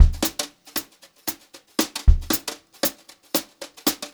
116JZBEAT2-L.wav